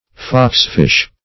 Search Result for " foxfish" : The Collaborative International Dictionary of English v.0.48: Foxfish \Fox"fish`\, n. (Zool.)